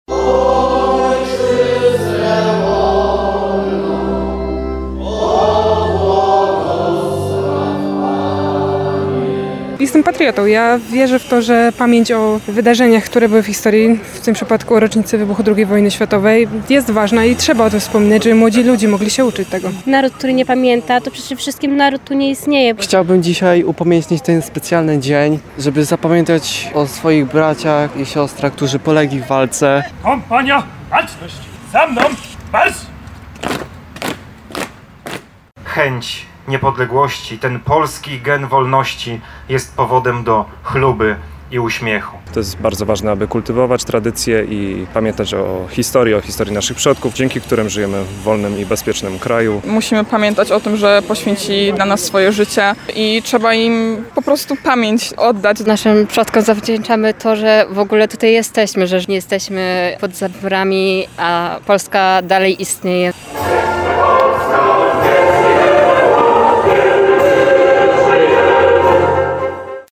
Na Starym Cmentarzu przy alejach Wolności odbyły się obchody 86. rocznicy wybuchu II wojny światowej. W samo południe przy Sądeckiej Piecie złożone zostały kwiaty i znicze.
Chce upamiętnić tych, którzy polegli w walce – mówili członkowie nowosądeckiego Związku Strzeleckiego Strzelec, który wraz ze Strażą Graniczną pełnili asystę honorową w trakcie uroczystości.